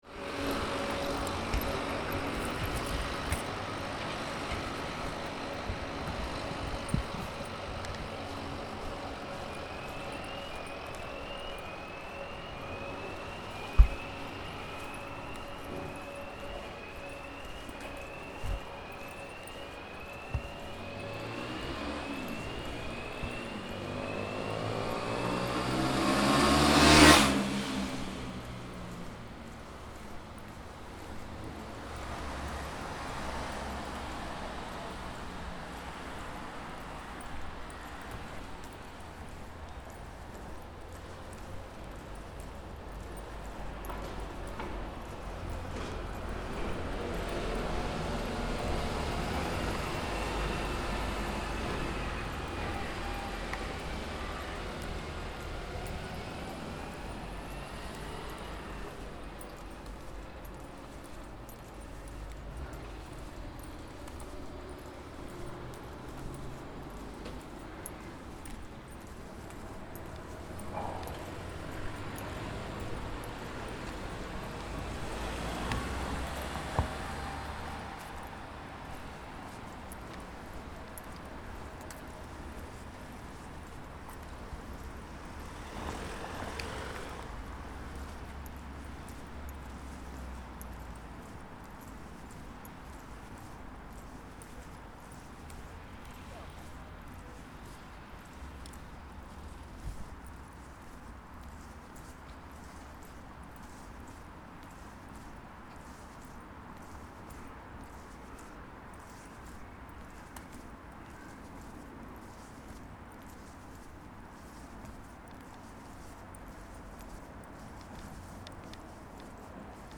Inspired by Social distancing, ‘Bournemouth Soundwalk’ is a Soundscape feature that documents the sounds of a typical Bournemouth journey when the environment is depopulated. Recorded March 16th Midday 2020 near Bournemouth town centre.